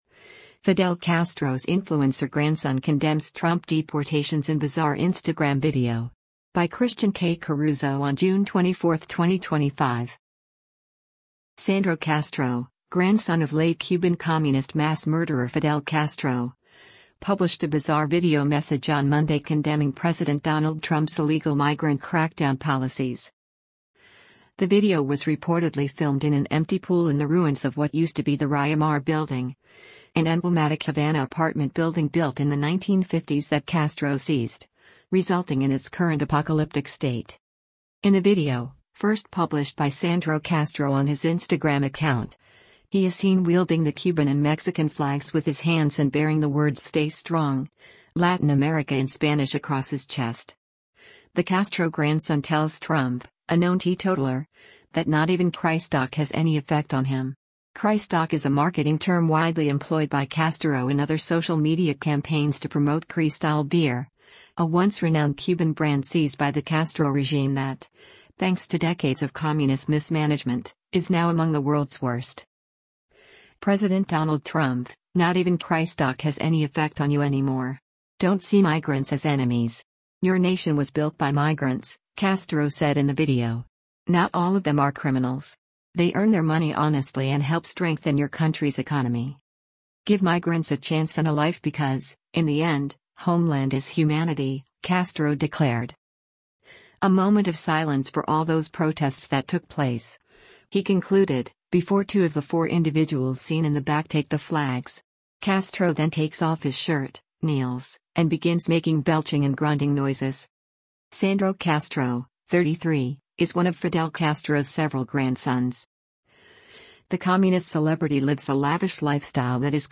The video was reportedly filmed in an empty pool in the ruins of what used to be the Riomar building, an emblematic Havana apartment building built in the 1950s that Castro seized, resulting in its current “apocalyptic” state.
Castro then takes off his shirt, kneels, and begins making belching and grunting noises.